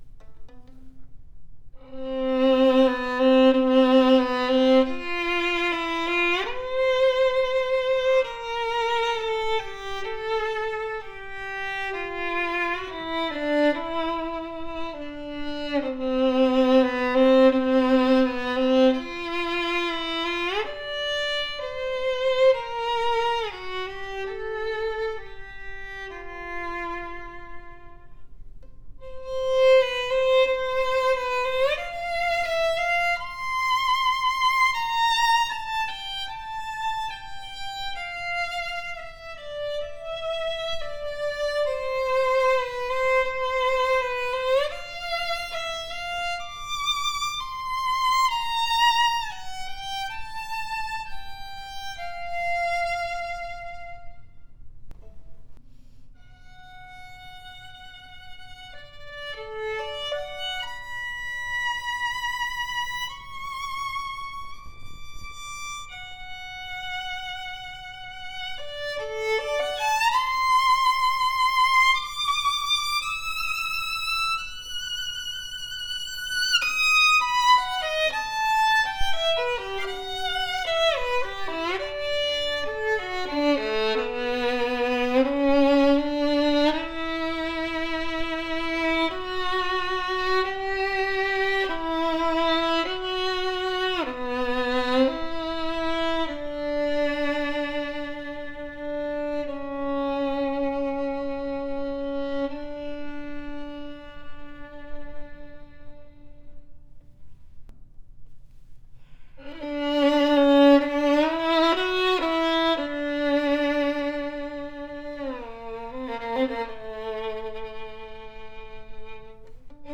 • Pro set up with Tonica string, German Aubert bridge.
A superior “Messiah” strad copy with resonant and DEEP tone, fast response and sonorous projection.
Ringing and penetrating higher register that projects well and not overly bright, open and pleasant to hear. Full and rounded G string with a deep vibrant voice.